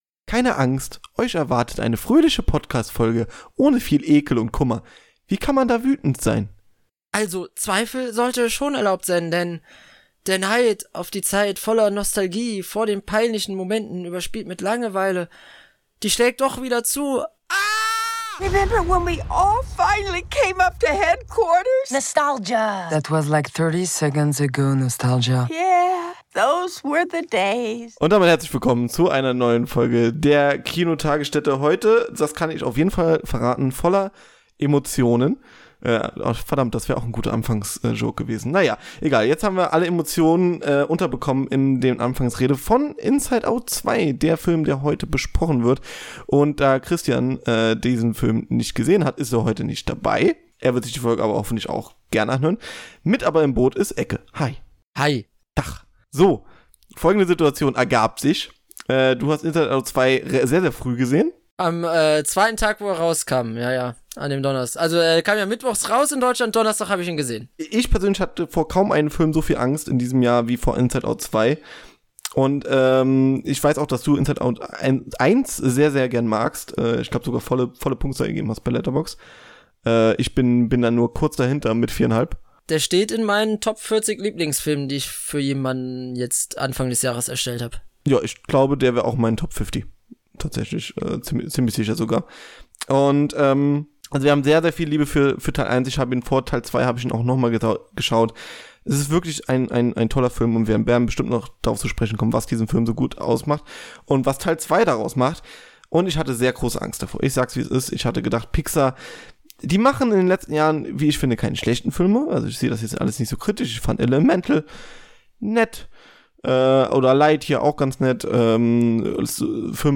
Alles steht Kopf 2 | Review-Talk ~ Die Kinotagesstätte Podcast